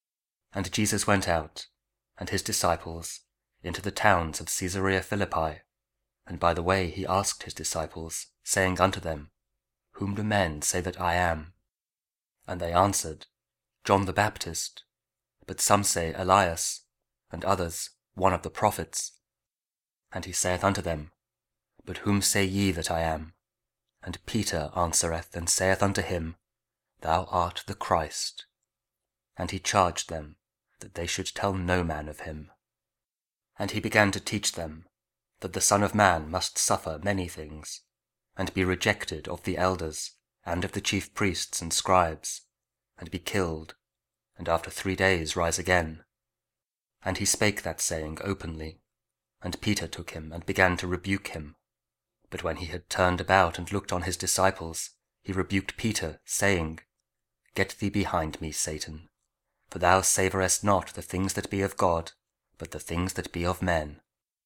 Mark 8: 27-35 – 24th Sunday Year B; also Week 6 Ordinary Time, Thursday (8: 27-33) (Audio Bible, Spoken Word)